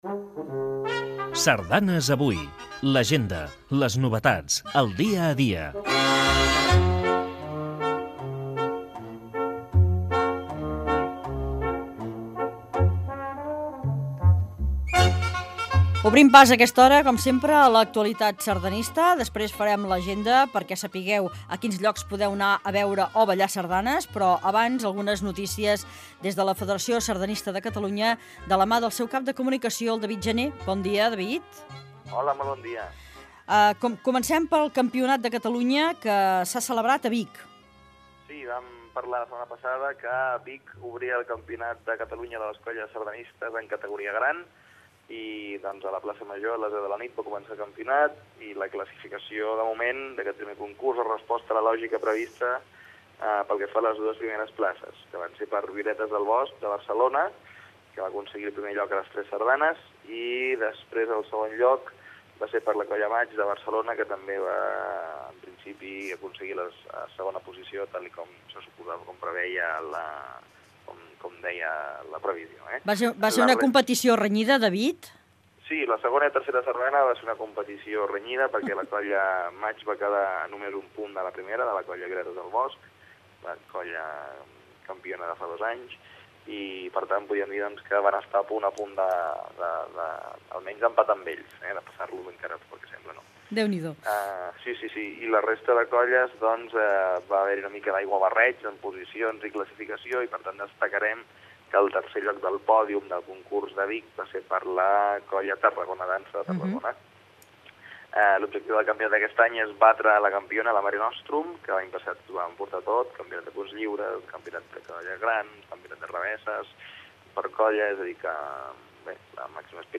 Entreteniment
FM
Fragment extret de l'arxiu sonor de COM Ràdio.